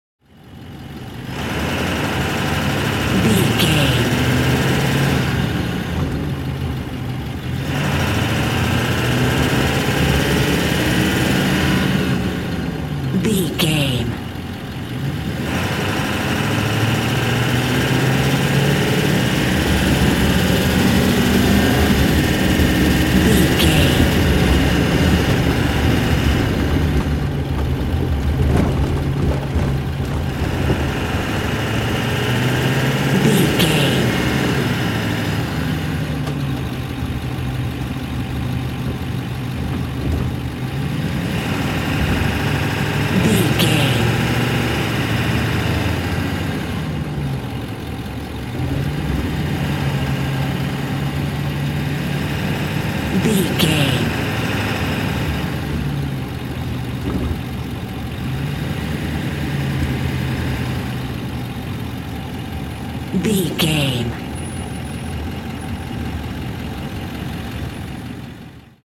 Ambulance Int Drive Diesel Engine Accelerate Fast Slow
Sound Effects
No